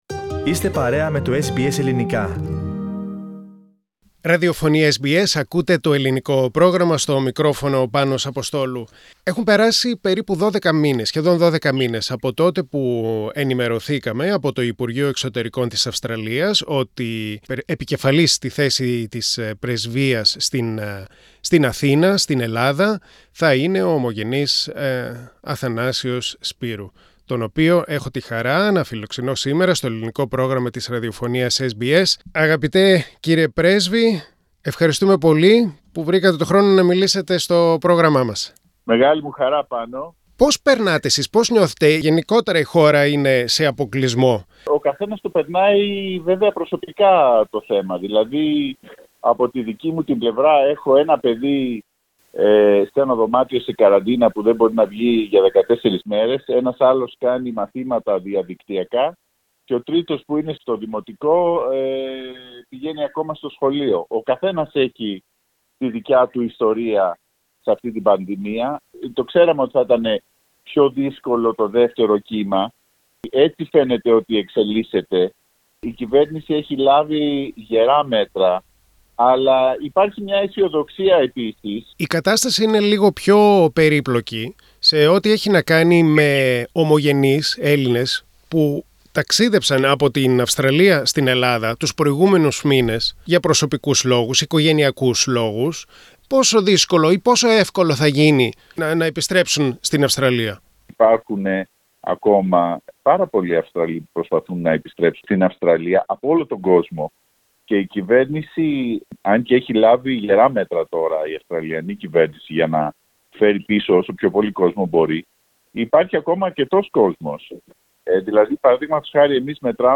Ο Πρέσβης της Αυστραλίας στην Ελλάδα, ομογενής Αθανάσιος Σπύρου μιλά στο SBS Greek για την πανδημία, τα ελληνοτουρκικά, τις σχέσεις Ελλάδας και Αυστραλίας και για την σύγχρονη ελληνική ποίηση.